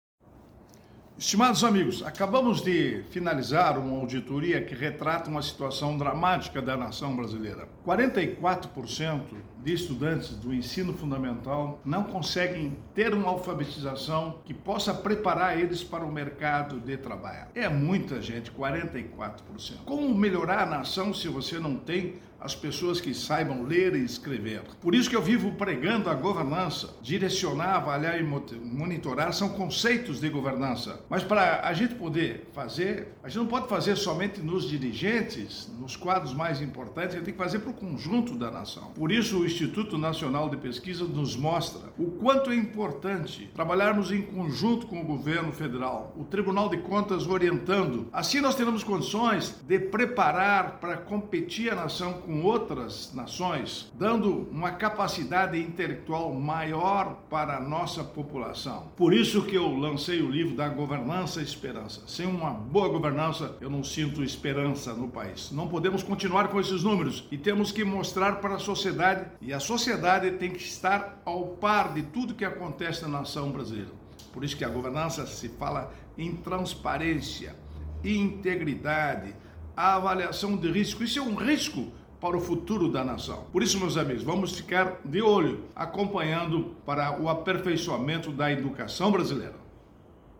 02-Ministro-prog-radio-educacao-tem-que-ser-aperfeicoada.mp3